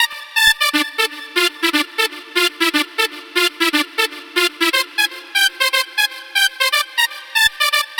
TSNRG2 Lead 006.wav